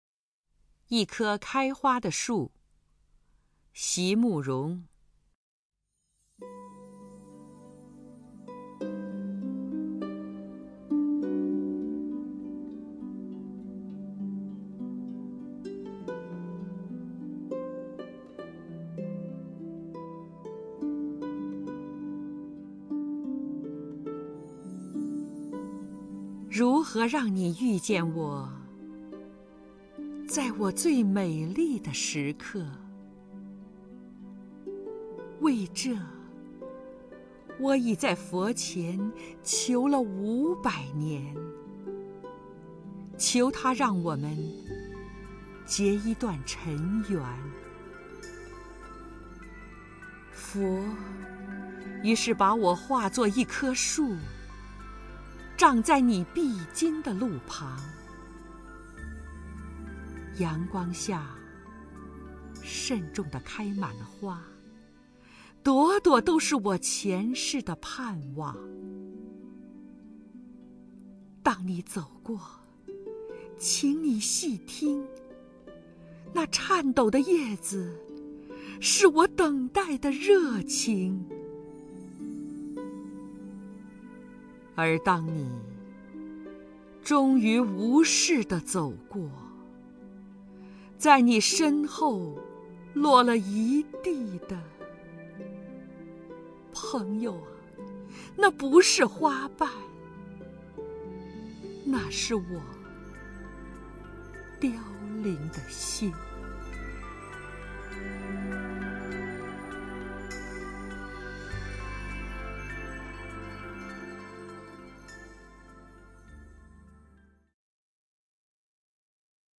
首页 视听 名家朗诵欣赏 张筠英
张筠英朗诵：《一棵开花的树》(席慕容)
YiKeKaiHuaDeShu_XiMuRong(ZhangJunYing).mp3